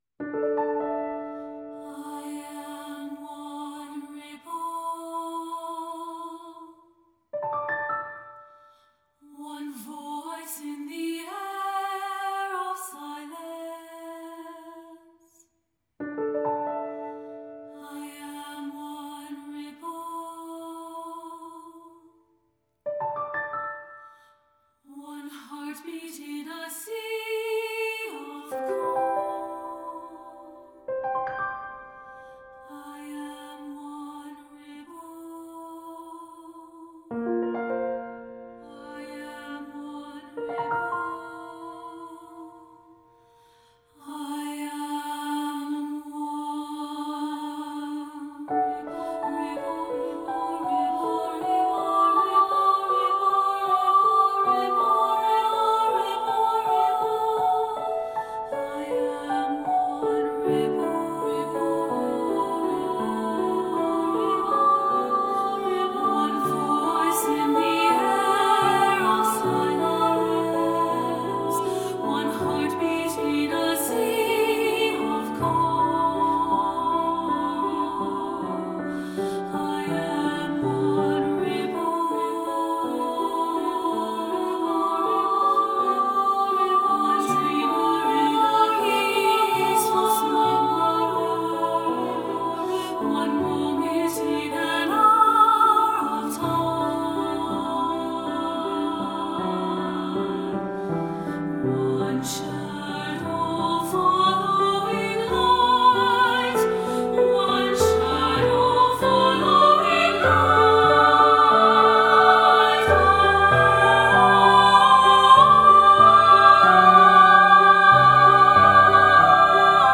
Voicing: SSA
Instrumentation: Piano